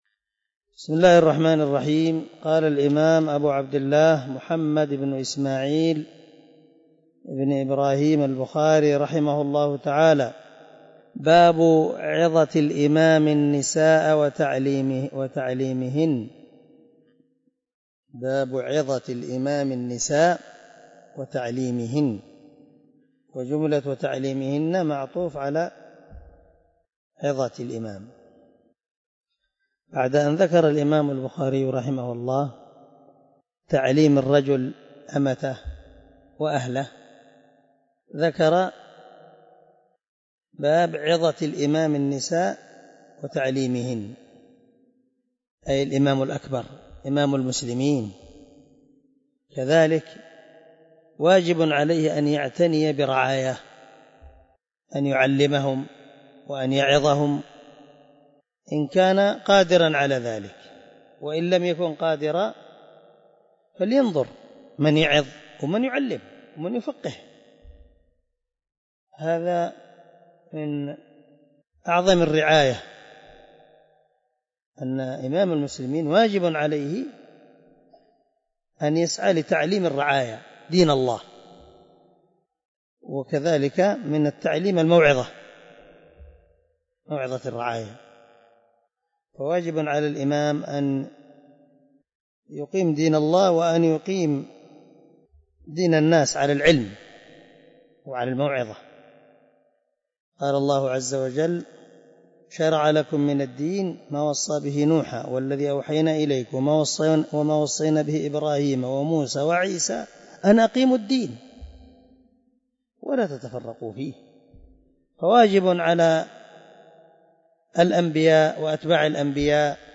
094الدرس 39 من شرح كتاب العلم حديث رقم ( 98 ) من صحيح البخاري